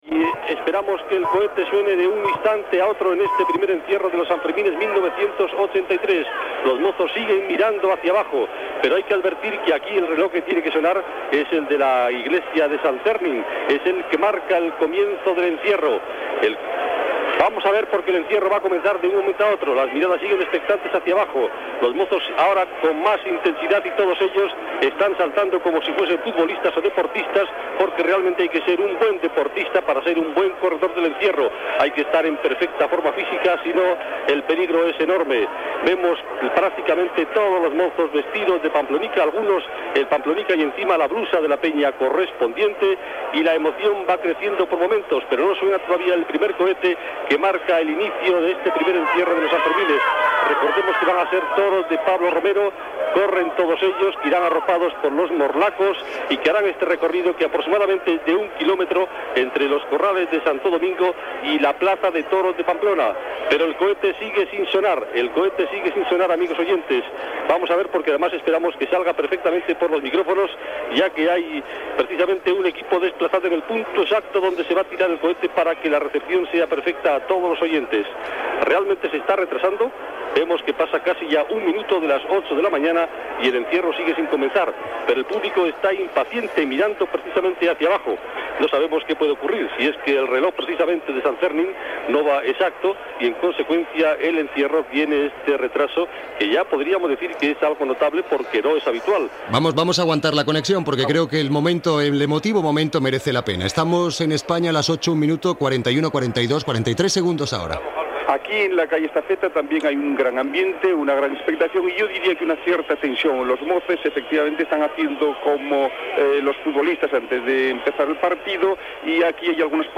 Transmissió des de Pamplona del "encierro" dels braus de Pablo Romero
Informatiu